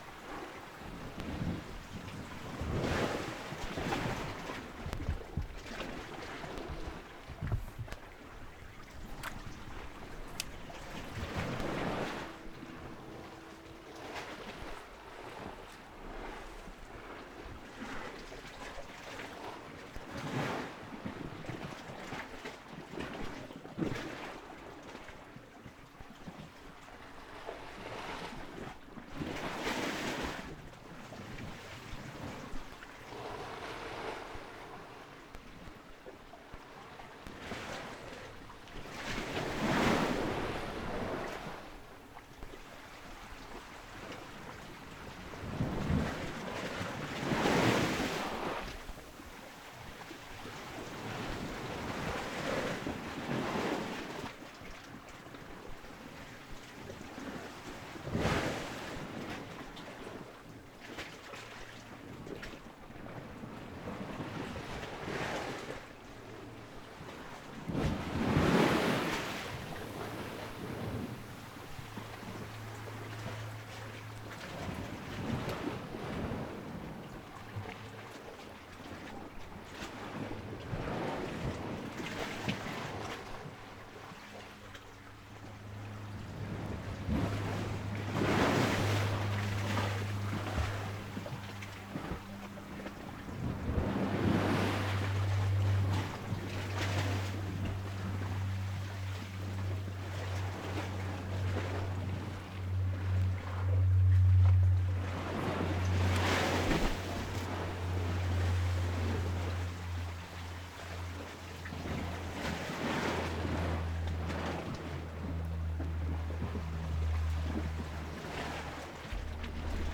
Zoom H6 (24 Bit, 96 kHz)
mit Rode NT4 Stereo-Kondensator Mikrofon
erste Aufnahme ohne Windschutz, 2. mit, dann plötzlich Regen
02 Wellen (Rode NT4).flac